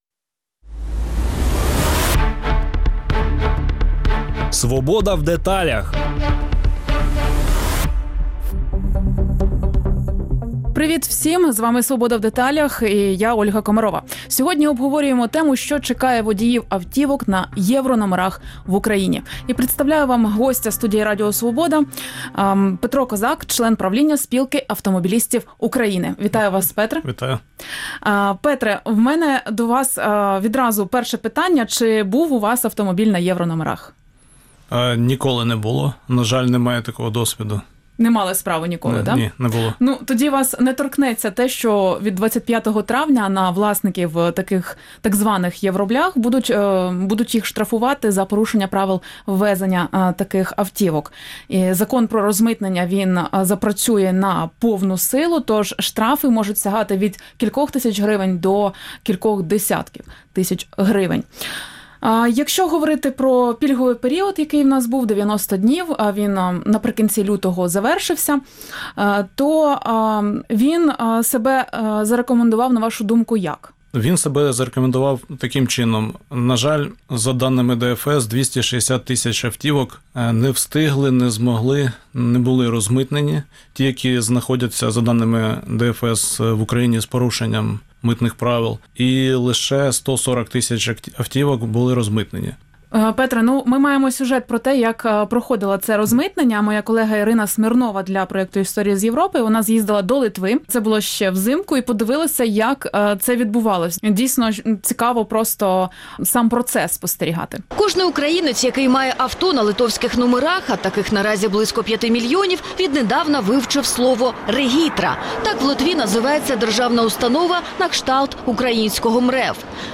Від 25 травня власників так званих «євроблях» будуть штрафувати за порушення правил ввезення автомобілів. Закон про розмитнення таких автівок має запрацювати на повну силу, тож штрафи можуть сягати від кількох до десятків тисяч гривень. Гість студії Радіо Свобода